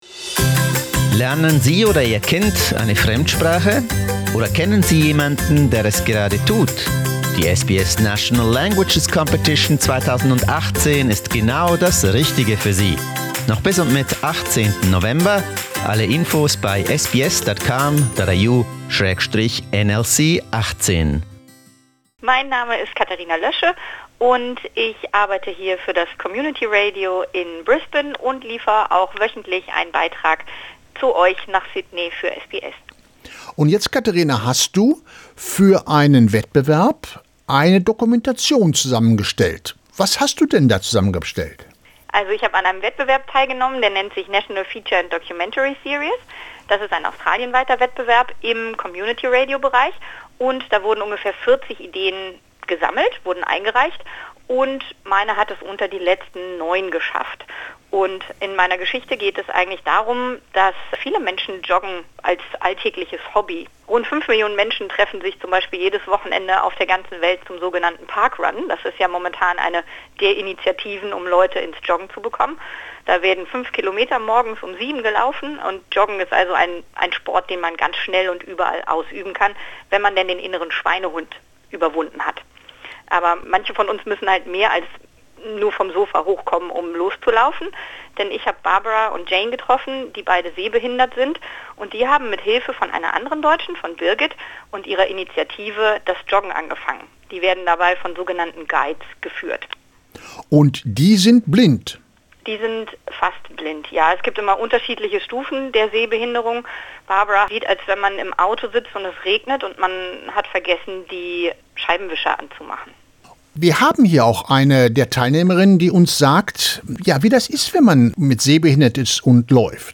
She was so impressed that she produced a radio documentary about her experience which she has entered into the National Features and Documentary Series competition.